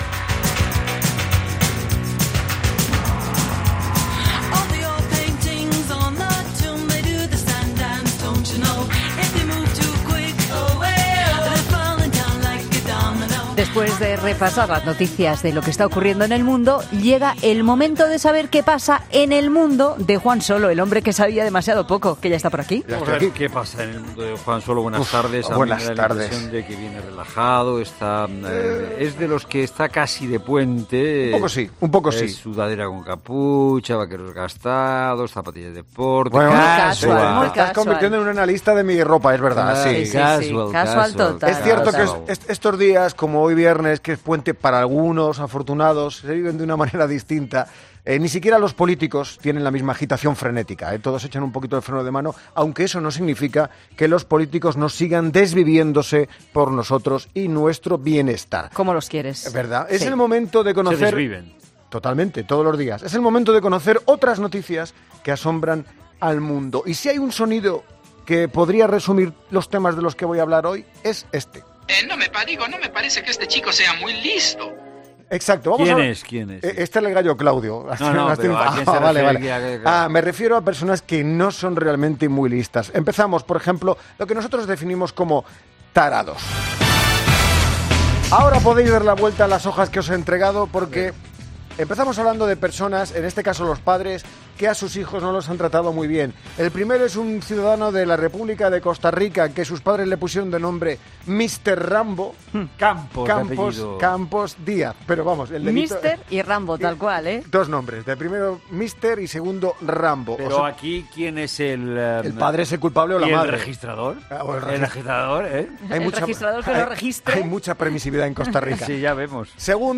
Humor: 'El hombre que sabía demasiado poco'